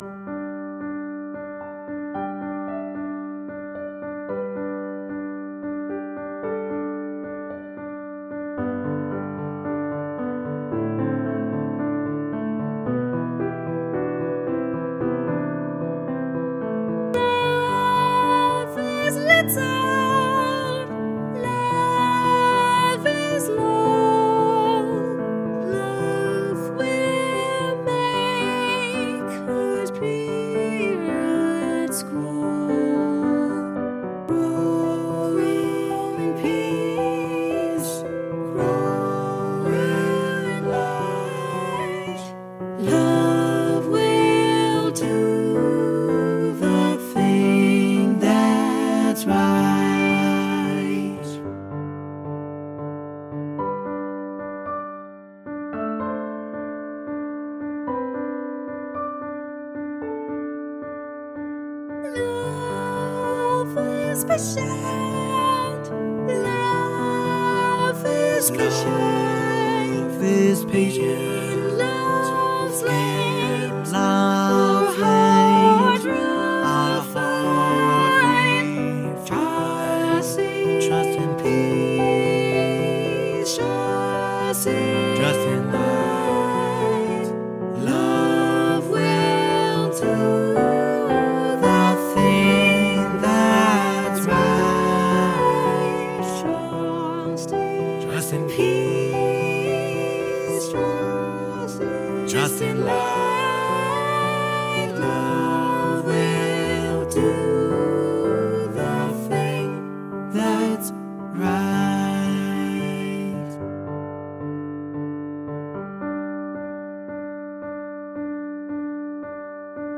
SAB + Piano.
SAB, Piano